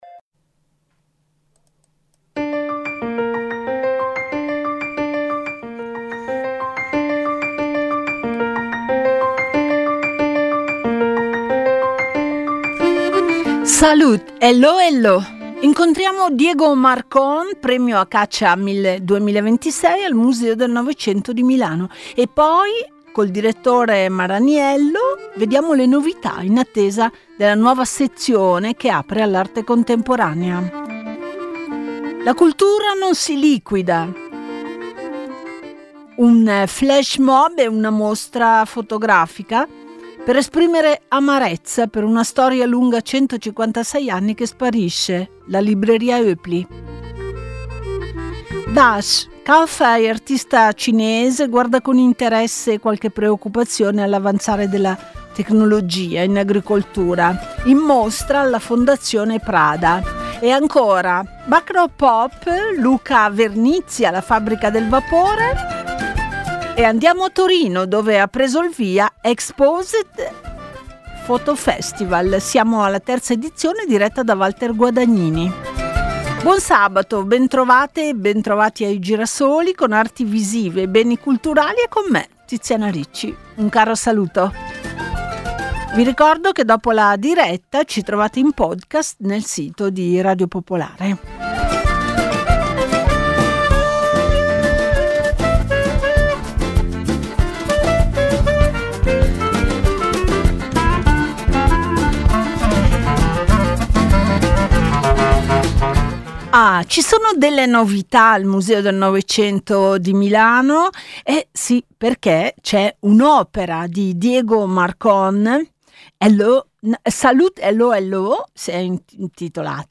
Ogni sabato alle 13.15, il programma esplora eventi culturali, offre interviste ai protagonisti dell'arte, e fornisce approfondimenti sui critici e sui giovani talenti.